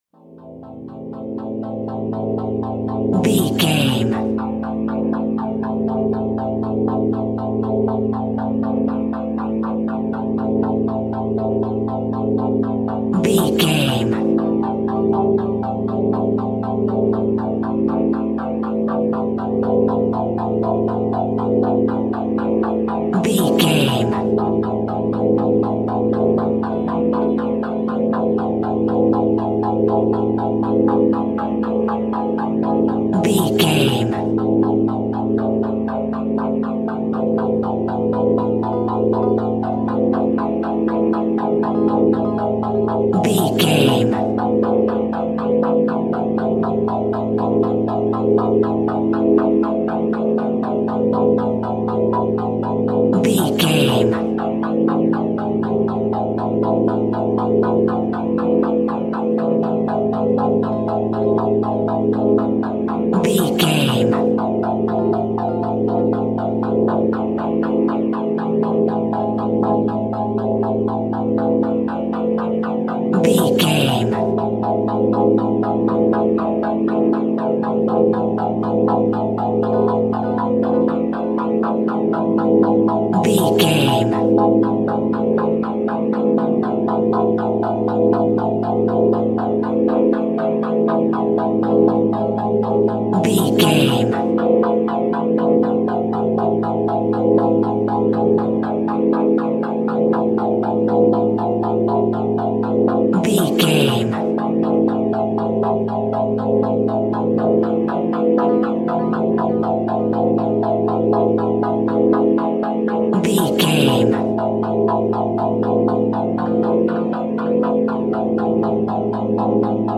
Atonal
tension
ominous
eerie
synthesiser
horror music